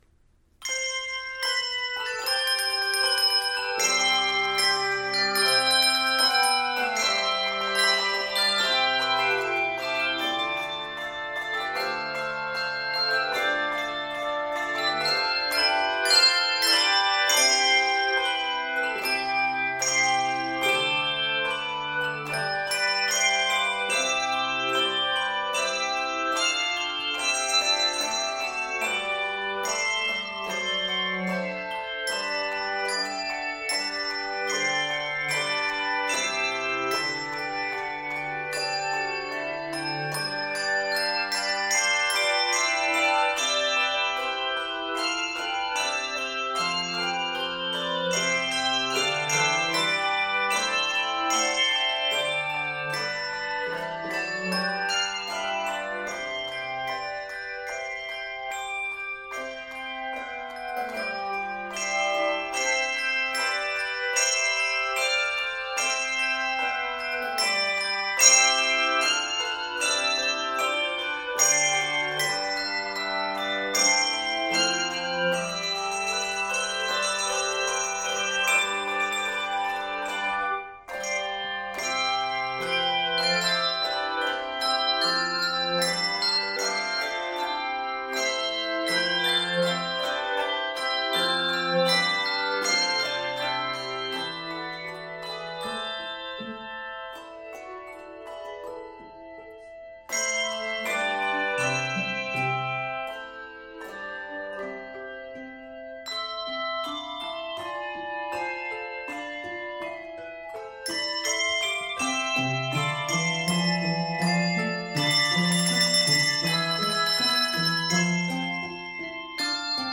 flowing eighth notes
Key of C Major.